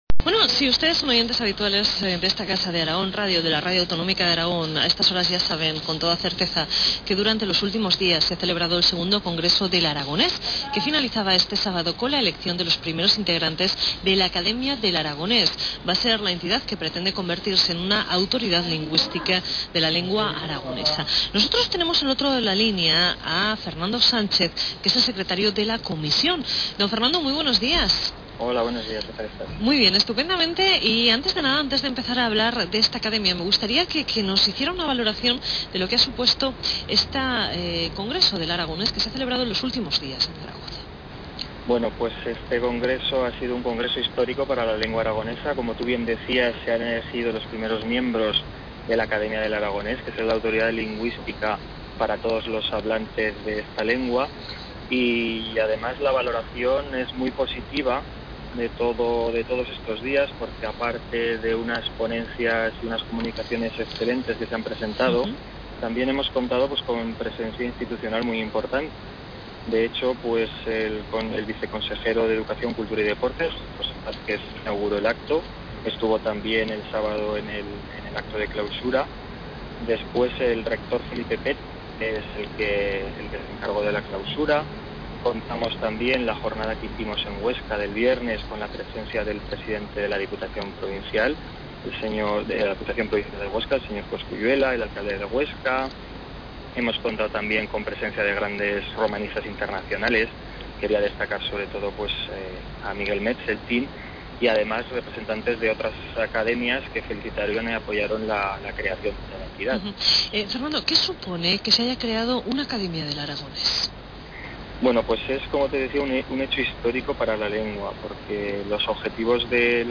Entrebista Aragón Radio, 17 de chulio (mp3, 5.7 Mb)